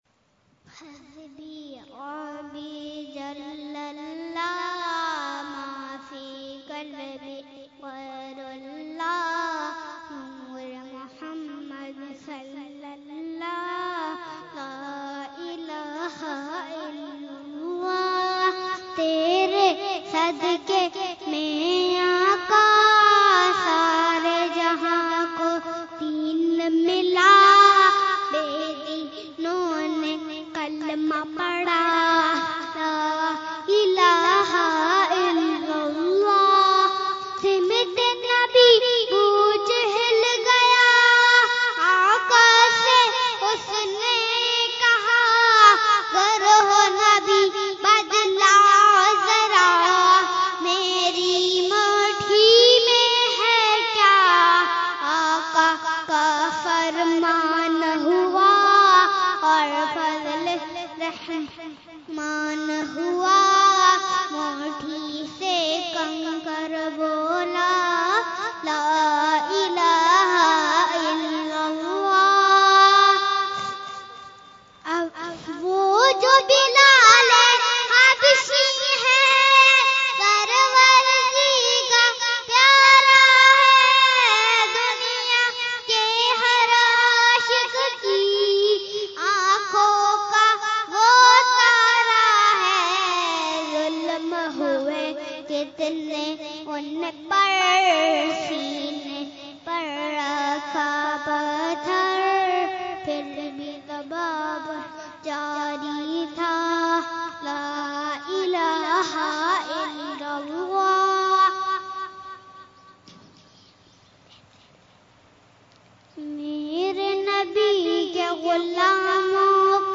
Category : Naat | Language : UrduEvent : Urs Ashraful Mashaikh 2018